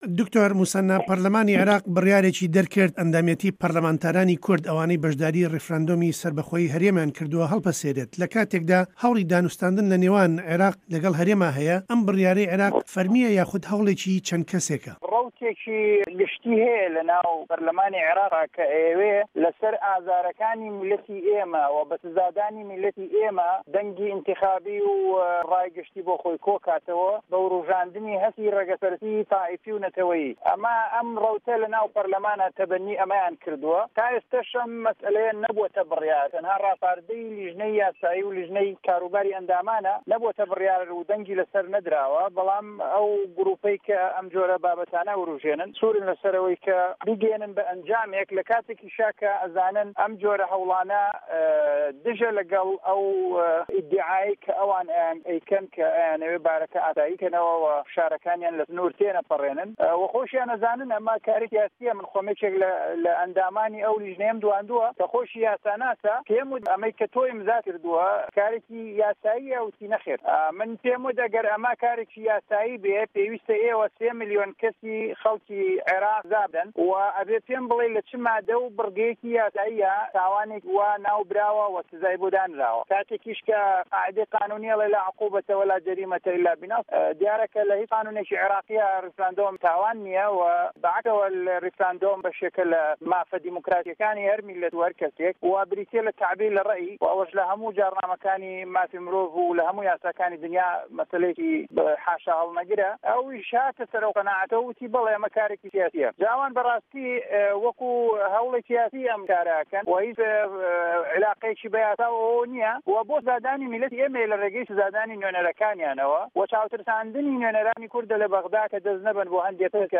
گفتووگۆ له‌گه‌ڵ دکتۆر مه‌سه‌نا ئه‌مین